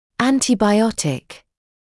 [ˌæntɪbaɪ’ɔtɪk][ˌэнтибай’отик]антибиотик; антибиотический